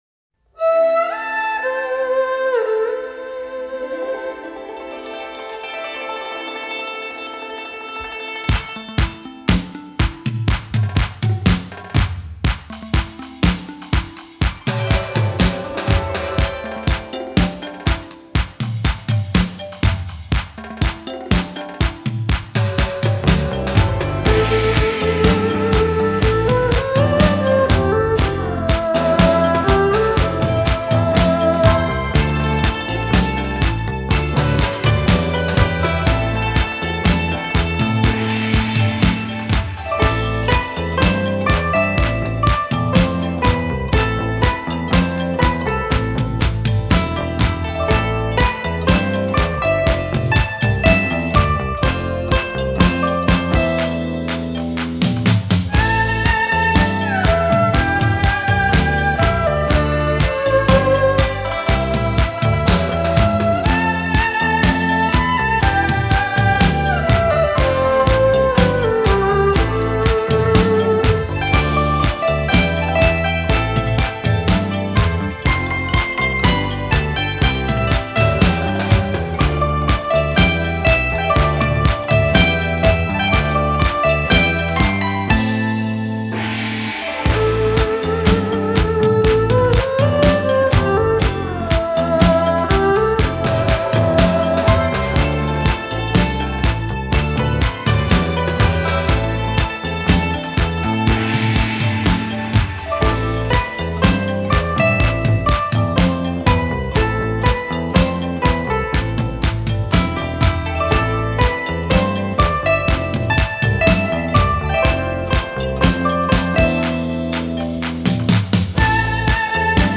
Тайская_музыка
Taqskaya_muzyka.mp3